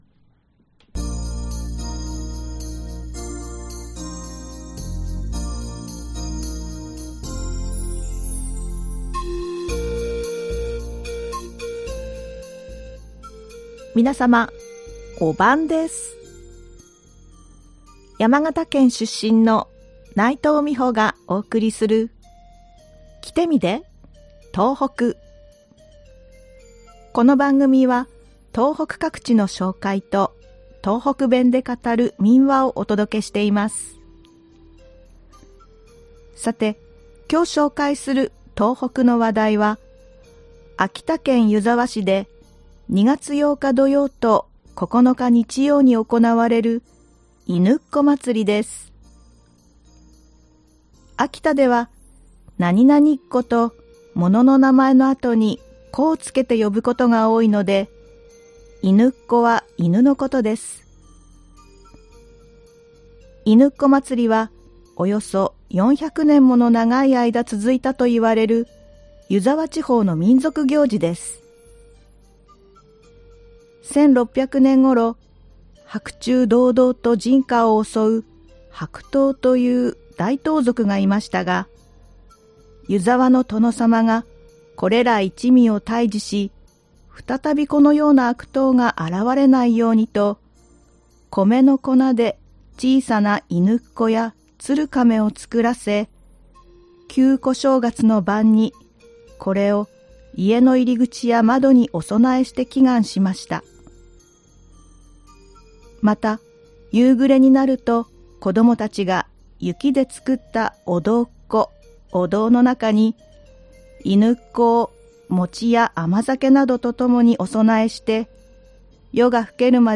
この番組は東北各地の紹介と、東北弁で語る民話をお届けしています。